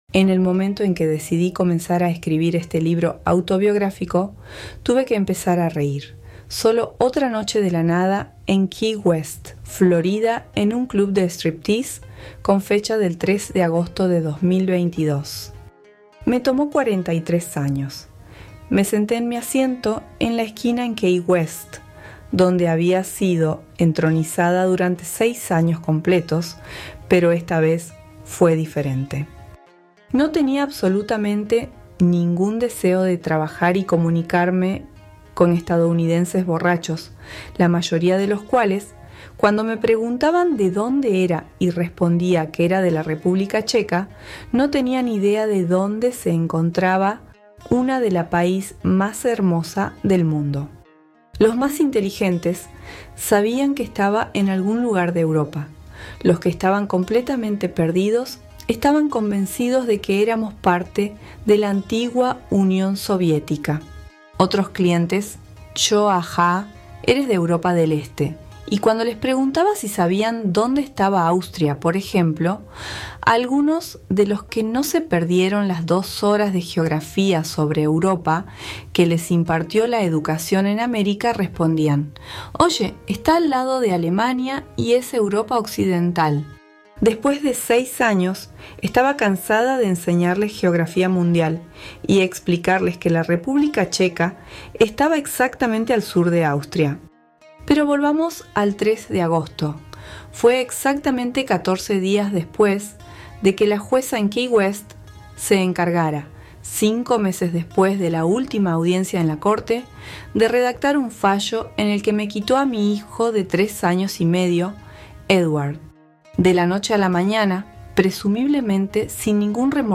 Historia de una Stripper (ES) – audiolibro – $24.99
Extracto de la introducción del libro